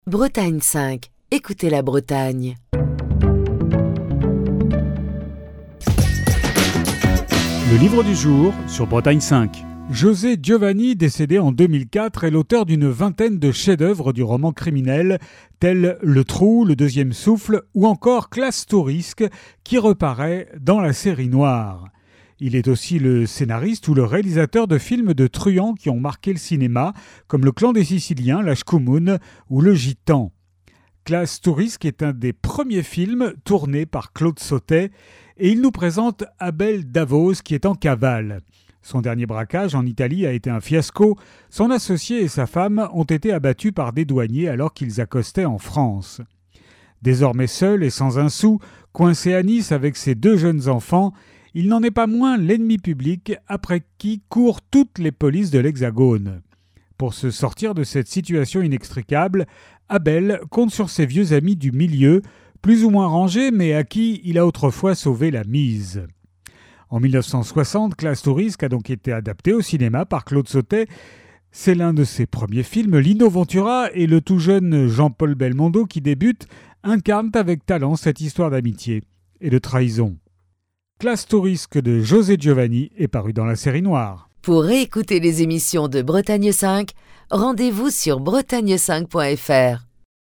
Chronique du 9 juillet 2024.